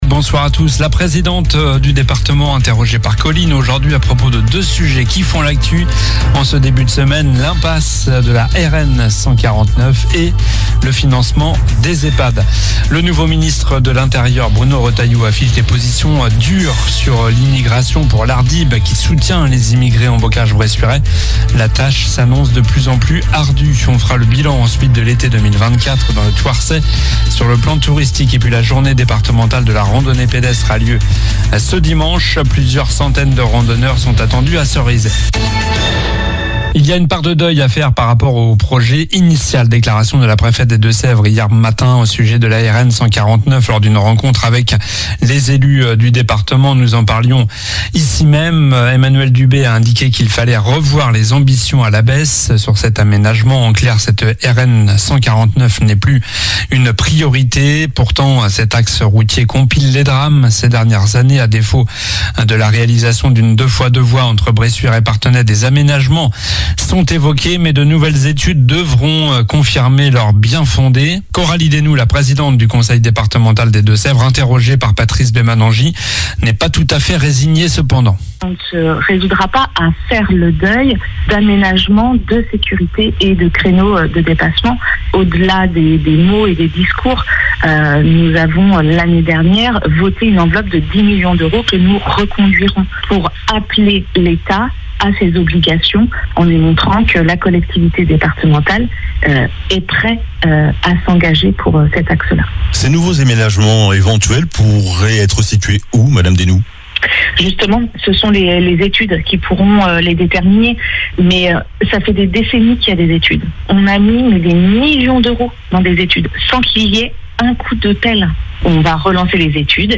Journal du mardi 24 septembre (soir)
La présidente du Département interrogée par Collines sur l'impasse de la RN 149 et le financement des EPHAD.